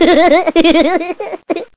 SFX呵呵呵的搞笑声音效下载
SFX音效